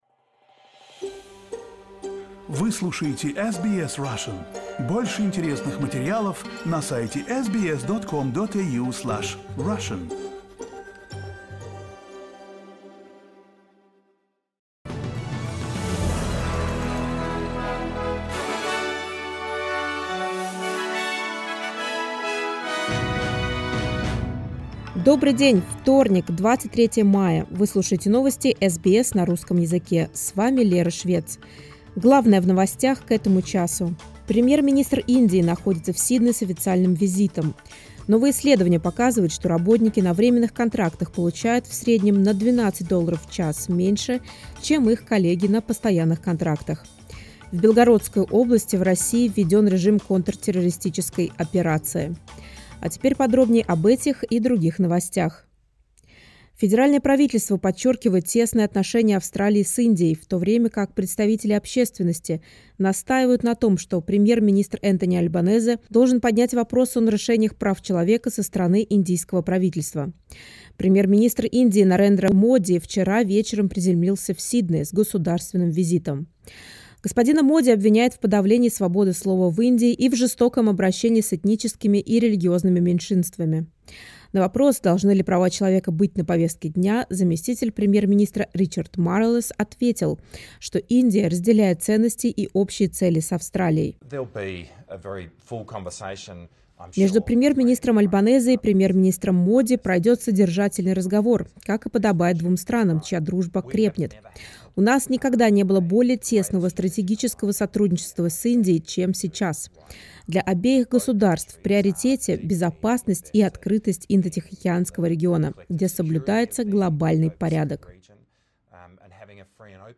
SBS news in Russian — 23.05.2023